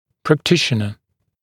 [præk’tɪʃənə][прэк’тишэнэ]практикующий врач